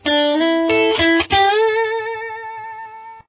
Here are some examples of some licks that you could create with it: